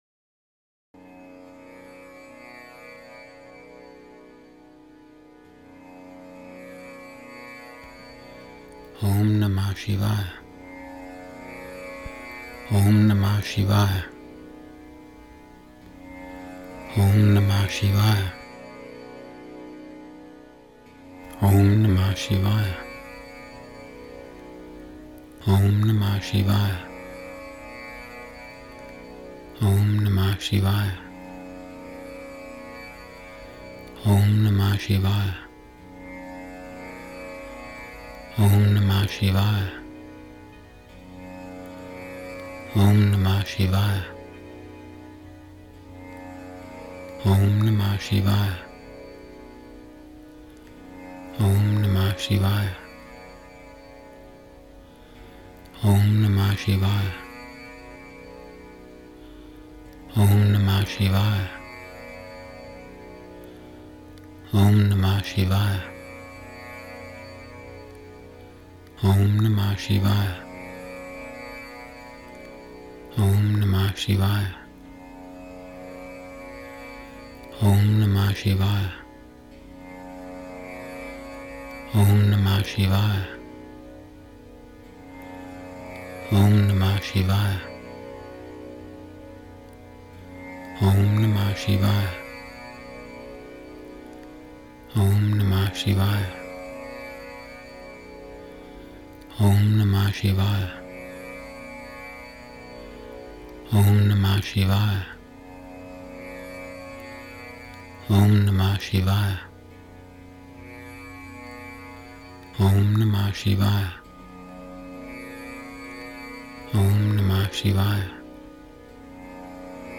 This practice is called japa, repetition of a mantra.